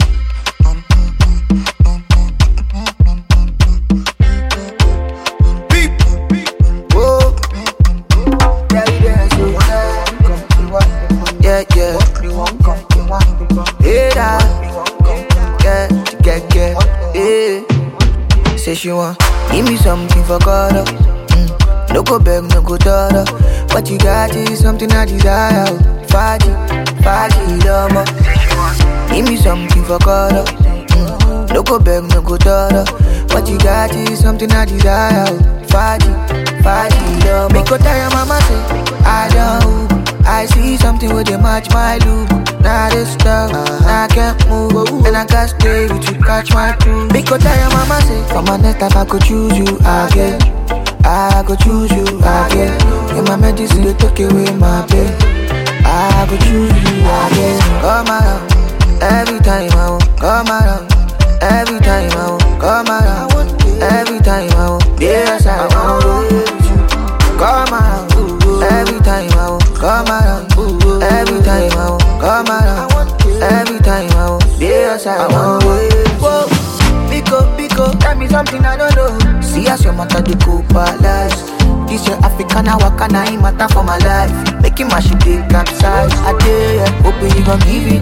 groovy single